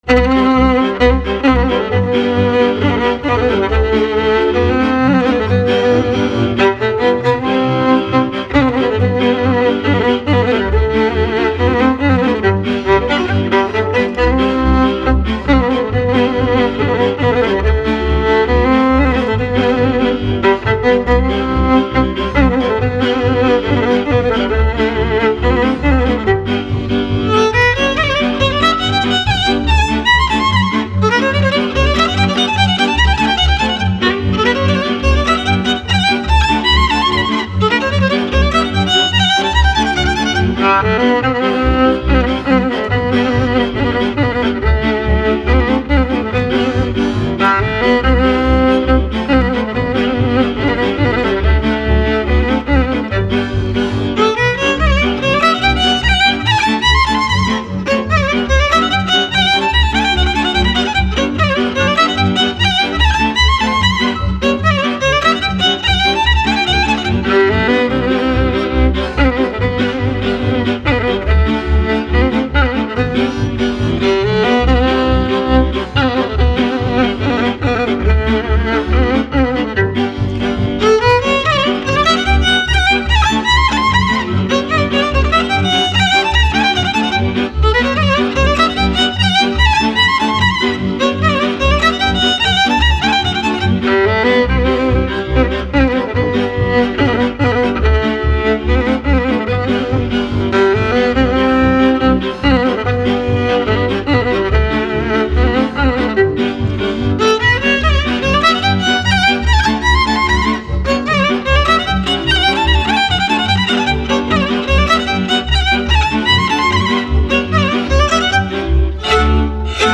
- realizează o bună omogenitate timbrală datorată celor trei instrumente din categoria “cu  coarde şi arcuş”
contrabasul  – frecvenţele grave
braciul cu trei coarde – frecvenţele medii
vioara – frecvenţele înalte